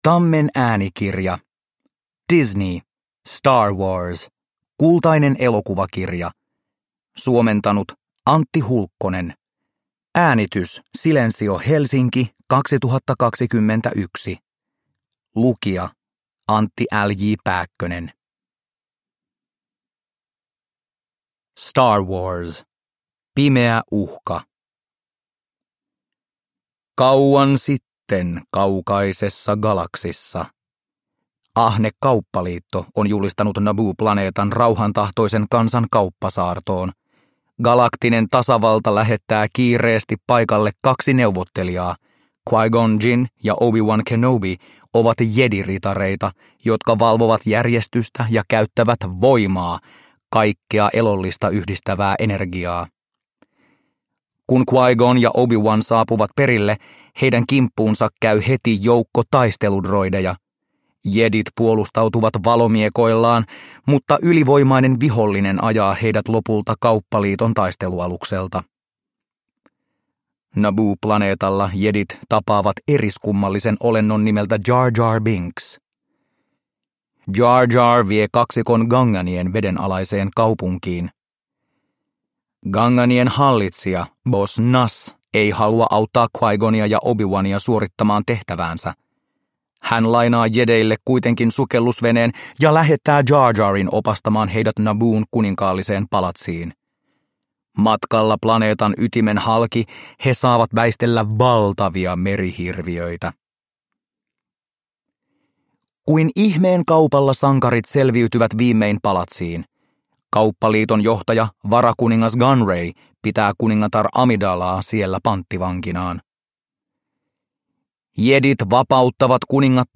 Star Wars. Kultainen elokuvakirja – Ljudbok – Laddas ner
Uppläsare: Antti L. J. Pääkkönen